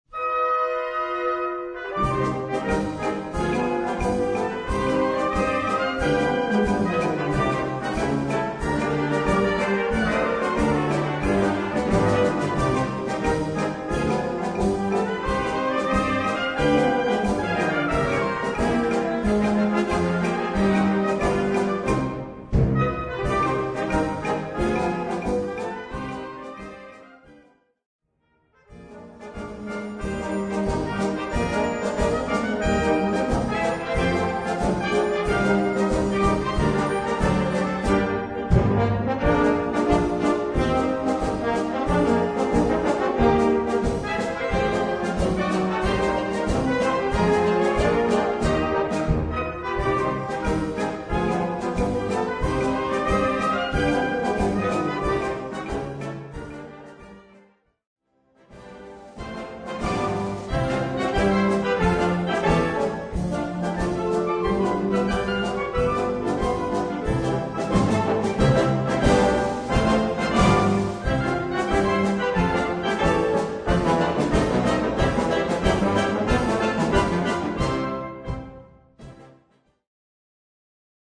Besetzung: Blasorchester
Choros